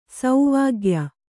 ♪ sauvāgya